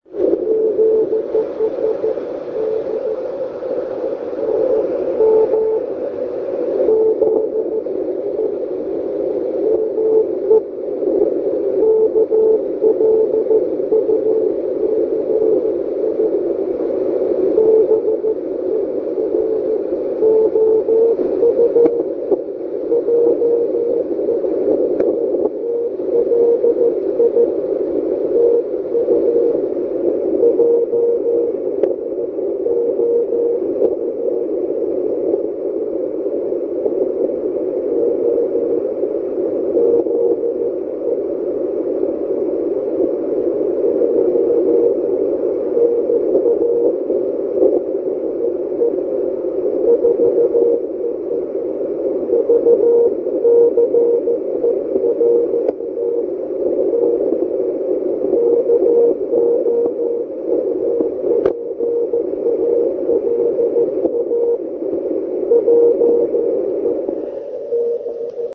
Je to takov� slabš�, ale jak si můžete všimnout - stoprocentně čiteln�.